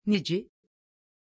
Aussprache von Nici
pronunciation-nici-tr.mp3